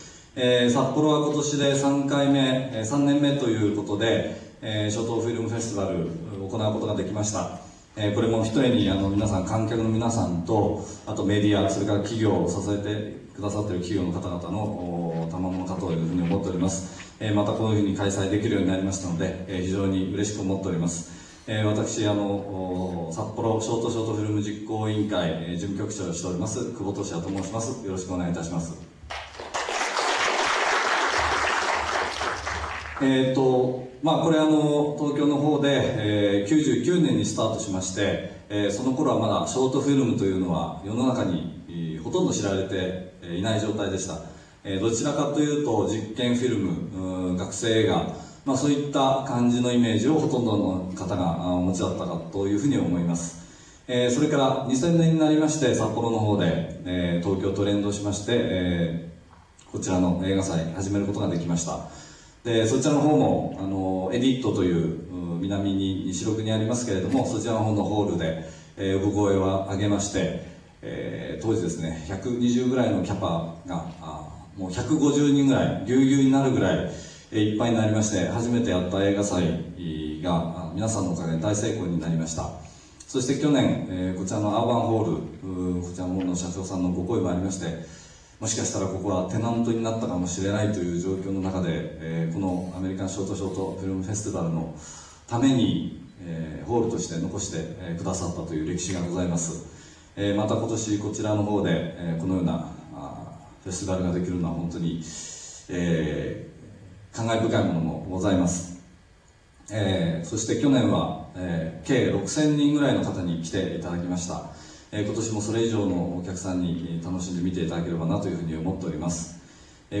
ショート・ショート・フィルム フェスティバル2002札幌・試写会が、4月24日にアーバンホール(札幌市中央区南3西5、アーバン札幌7階)で開かれた。